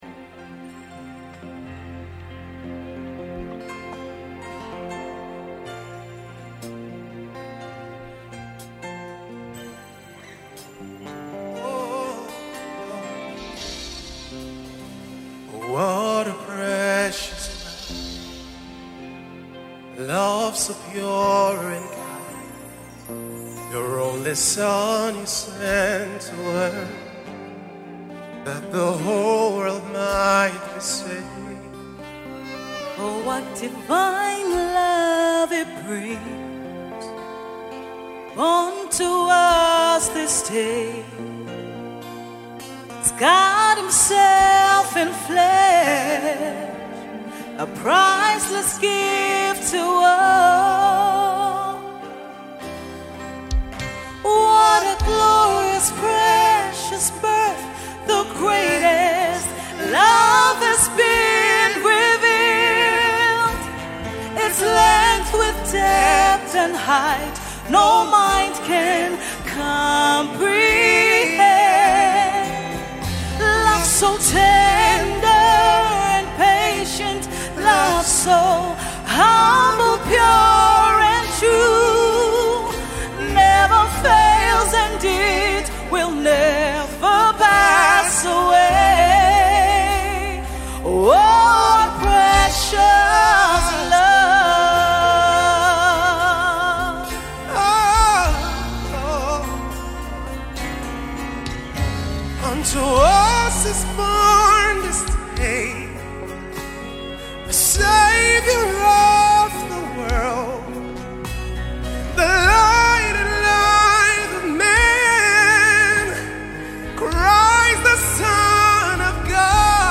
MORE CAROL SONGS